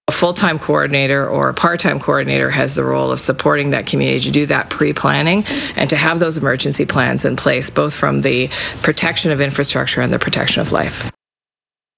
Federal Indigenous Relations Minister Patty Hadjuexplains, details the the role of the coordinator.
patty-hadju-federal-indigenous-relations-minister-on-wildfire-support.mp3